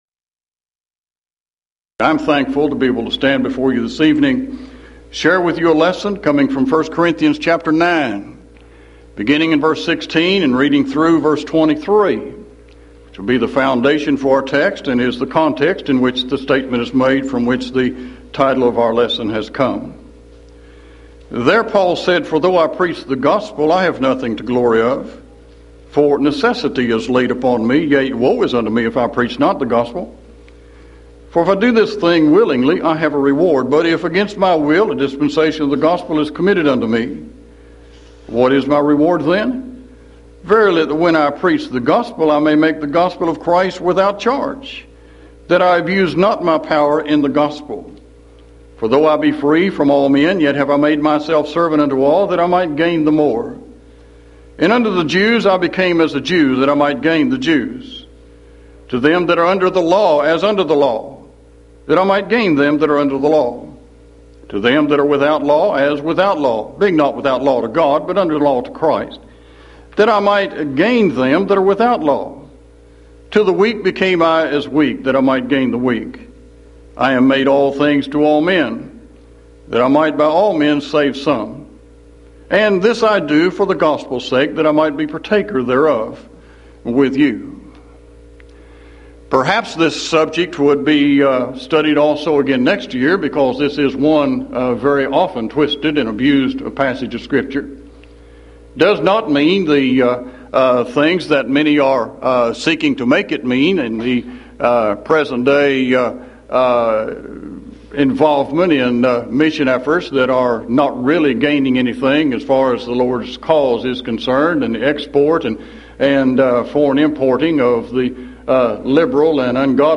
Event: 1994 Mid-West Lectures
Filed Under (Topics): Preaching